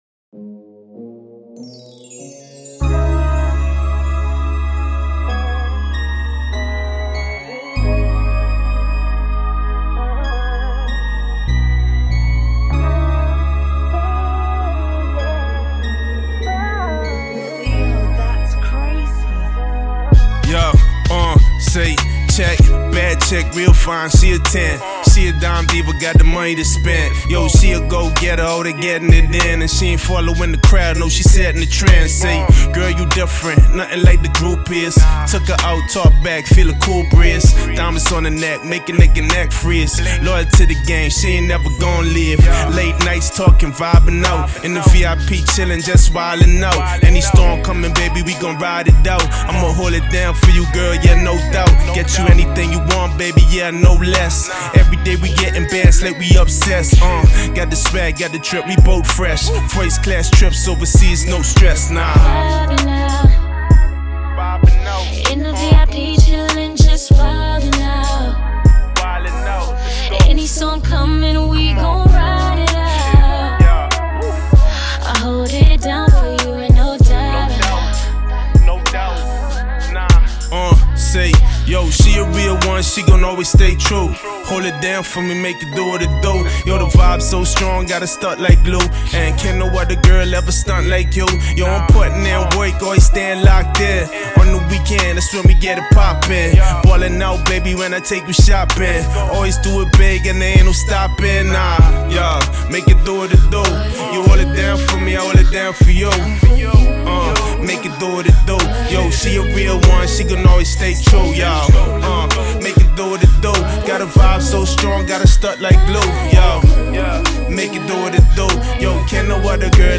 This is a mp3 acapella file and does not include stems